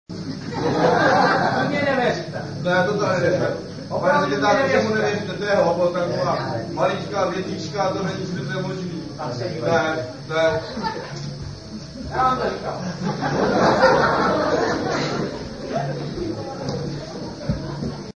Vystoupení se konalo 29. června 1999.
Za jeden večer jsme zvládli dvě představení.